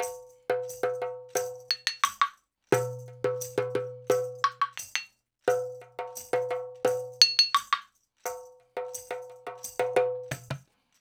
87-PERC1.wav